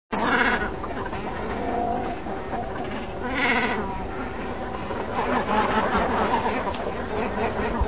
głosy